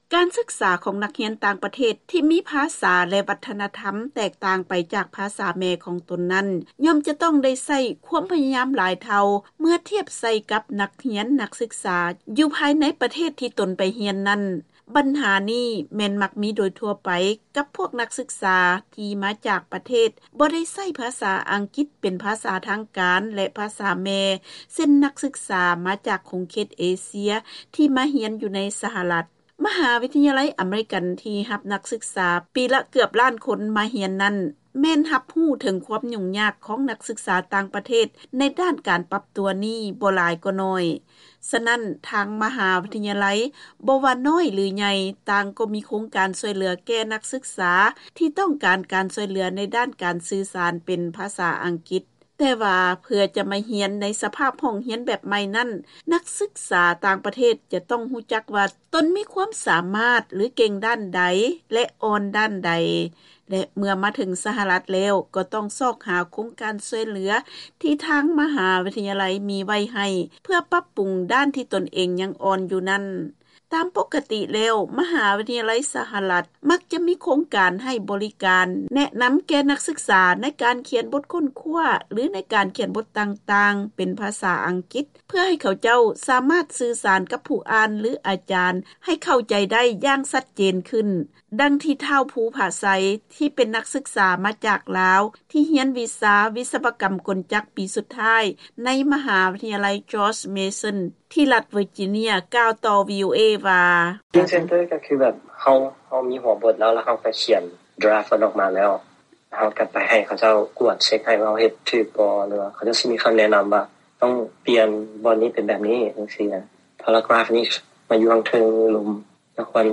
ເຊີນຟັງລາຍງານກ່ຽວກັບໂຄງການຊ່ວຍເຫລືອຂອງມະຫາວິທະຍາໄລ ໃນດ້ານການປັບປຸງພາສາອັງກິດໃຫ້ດີຂຶ້ນ